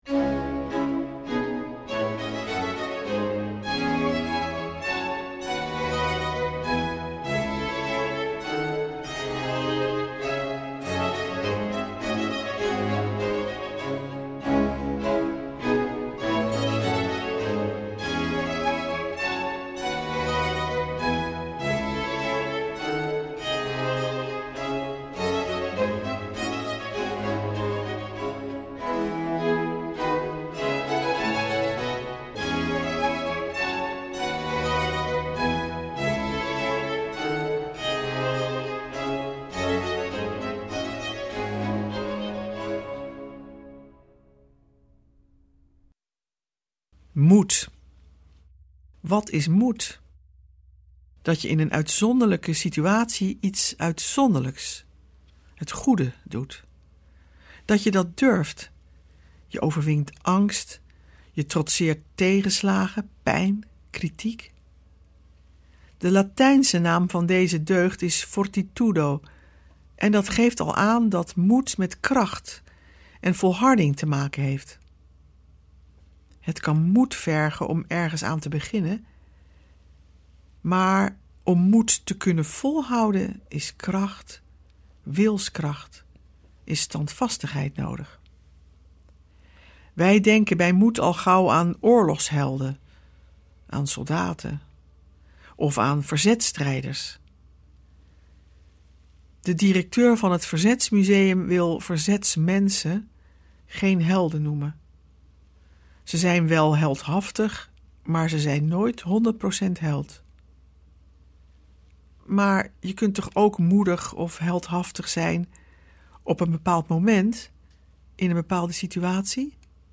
Meditaties Op Weg Naar Pasen 2023